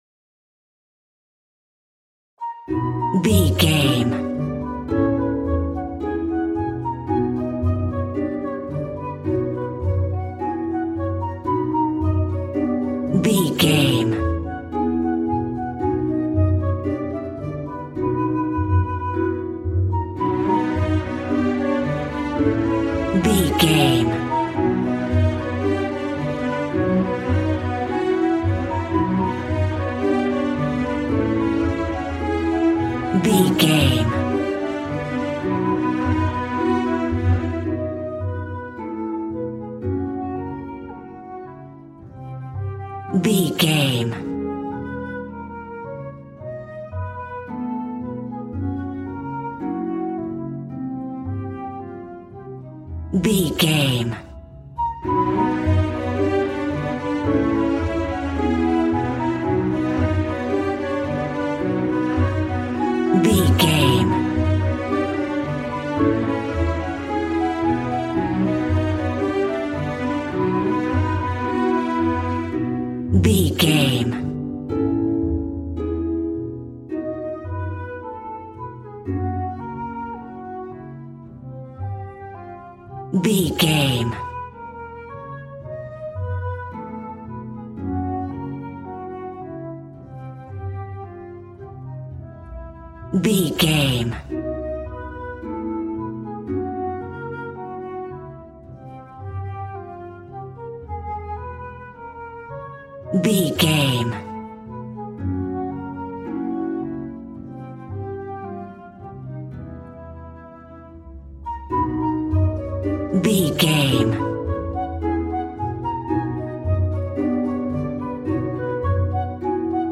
Ionian/Major
dramatic
powerful
epic
percussion
violin
cello